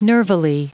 Prononciation du mot nervily en anglais (fichier audio)
Prononciation du mot : nervily